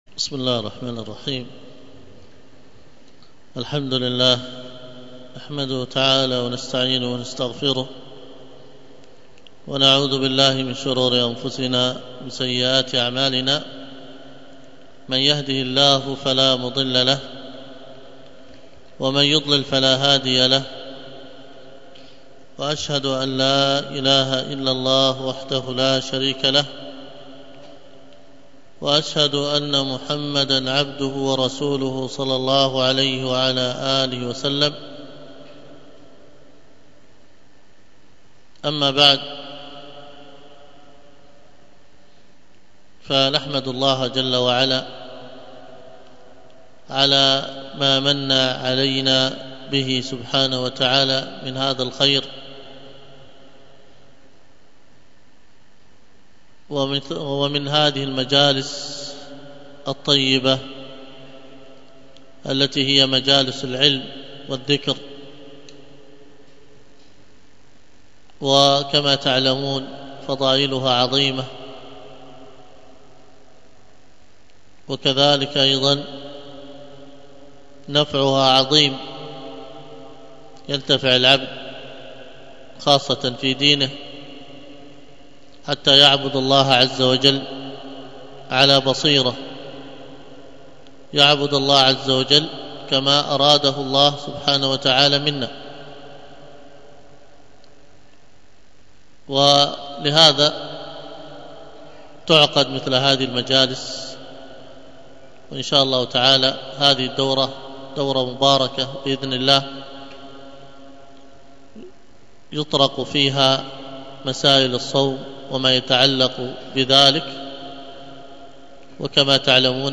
المحاضرة بعنوان آداب ومسائل رمضانية، والتي كانت ضمن دورة زاد الصائم الثانية بمسجد ابن عباس بمنطقة زغفة